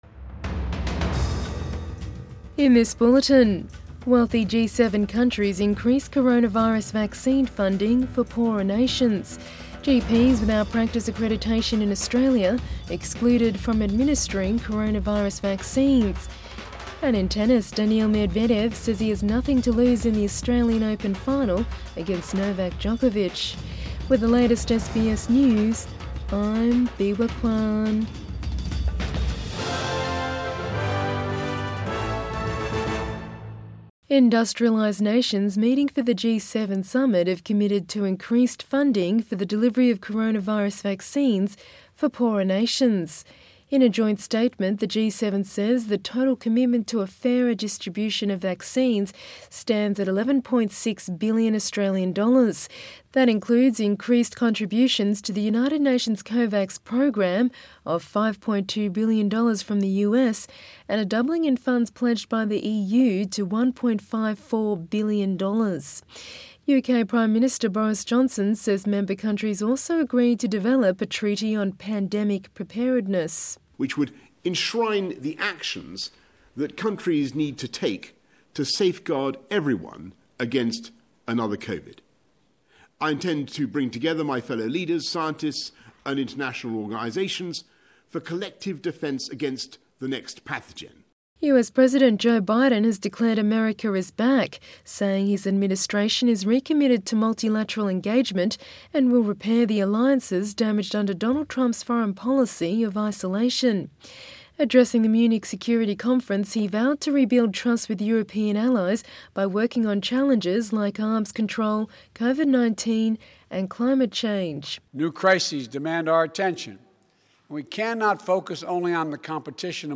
AM bulletin 20 February 2021